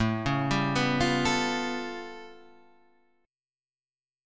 A Augmented 9th
A+9 chord {5 4 5 4 6 5} chord